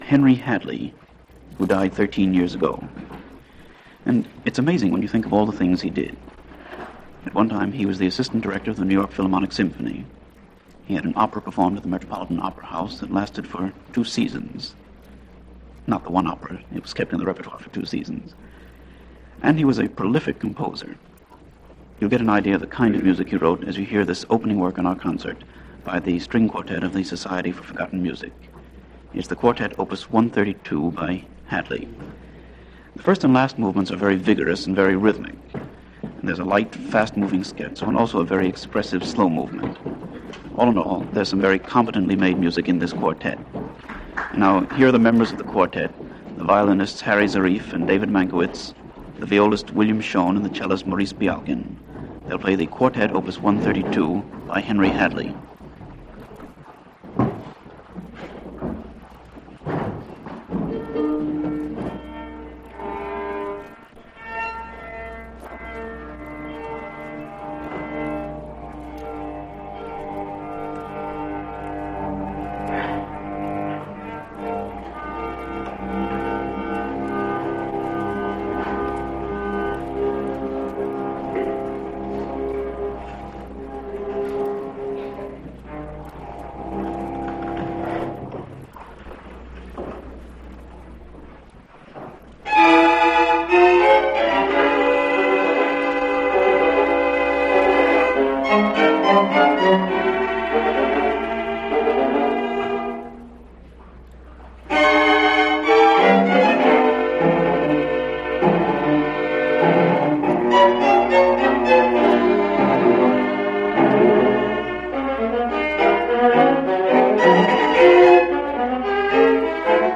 Music of Henry Hadley this weekend. His String Quartet, played by members of the Society For Forgotten Music String Quartet in this concert broadcast of February 13, 1950.